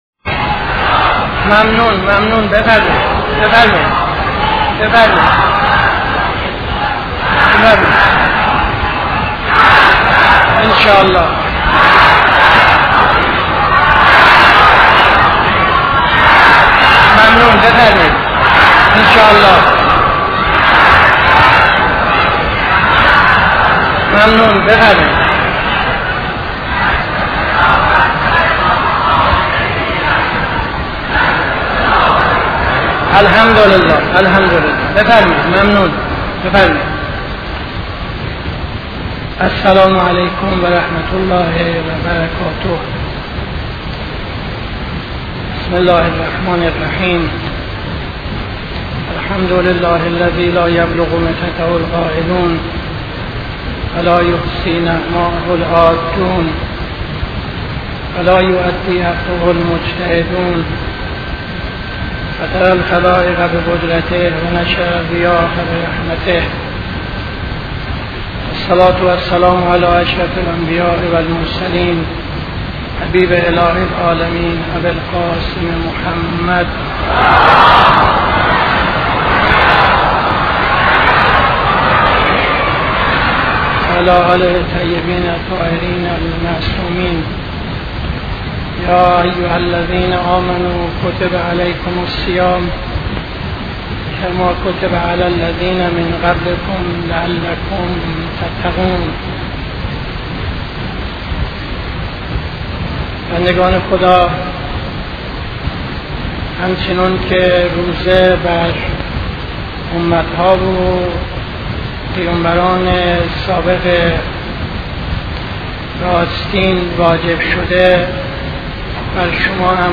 خطبه اول نماز جمعه 06-11-74